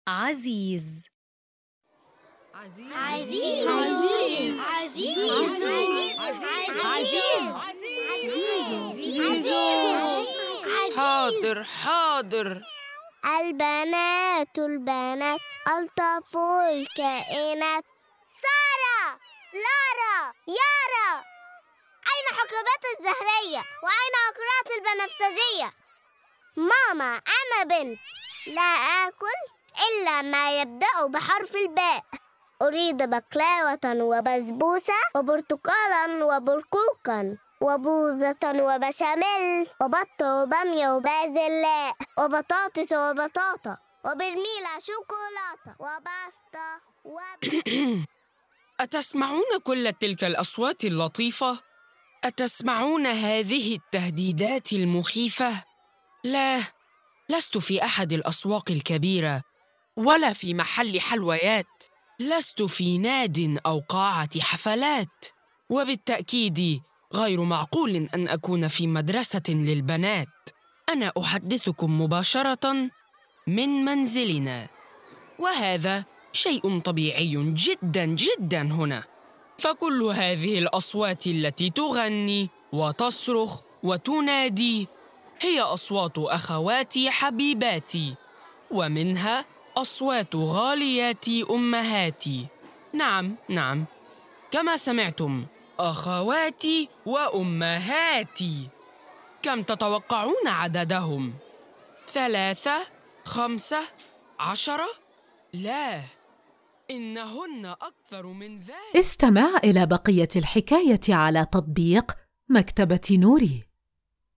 كل قصة يتم تقديمها بصوت جذاب جميل، مع مؤثرات لتحفيز الطفل على التفاعل والاندماج في عالم الحكايات.